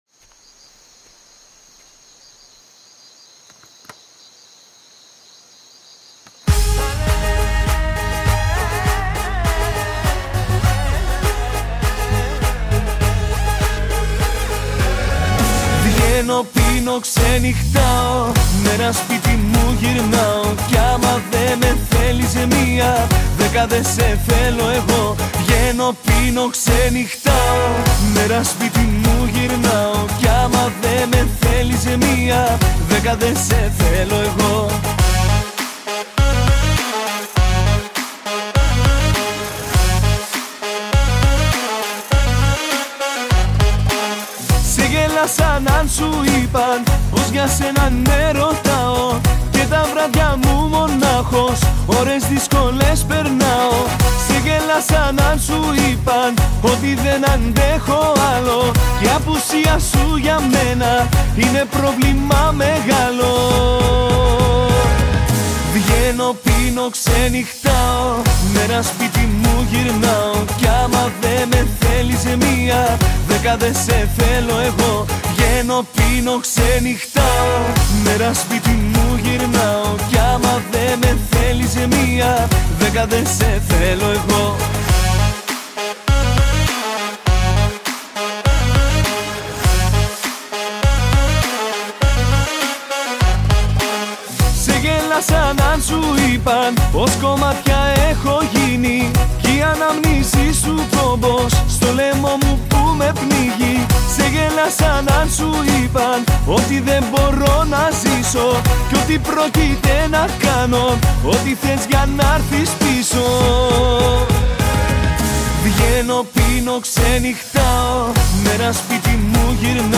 Жанр: ΠΟΠ και ΛΑΪΚΆ